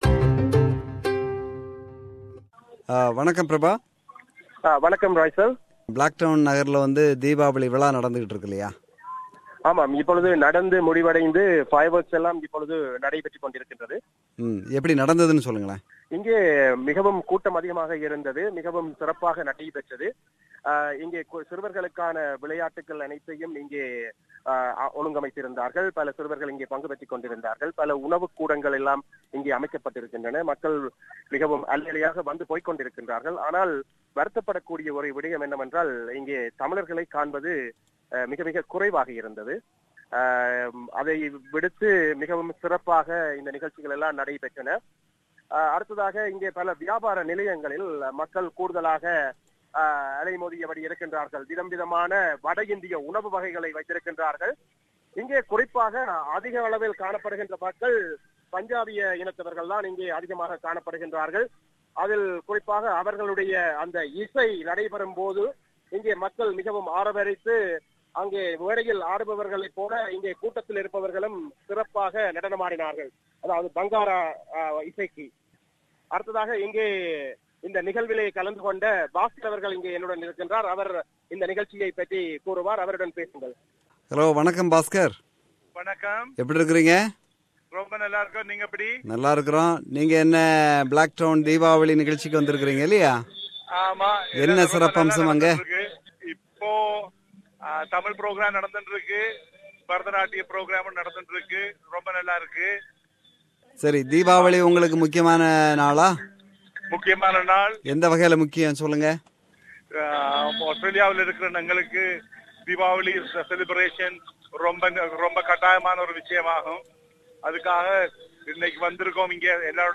filed this report from SYDNEY Blacktown Showgrounds on Sunday 16th October, 2016.